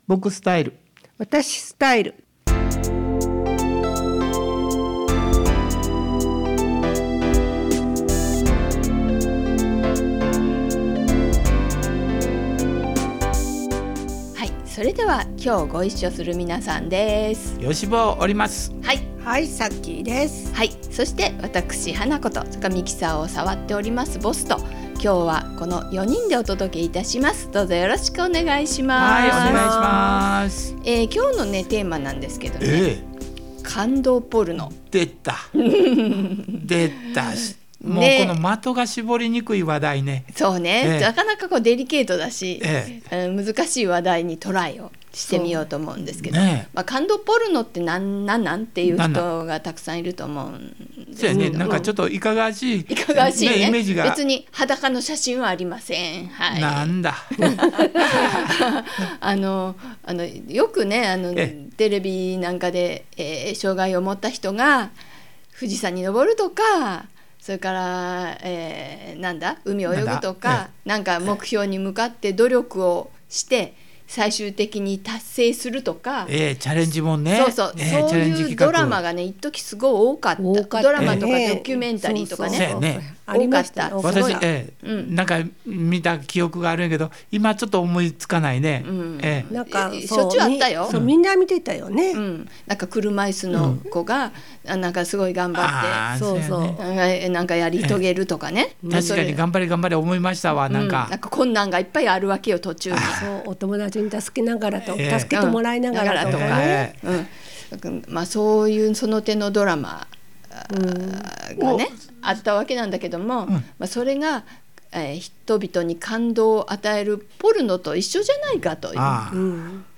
場所：相生市総合福祉会館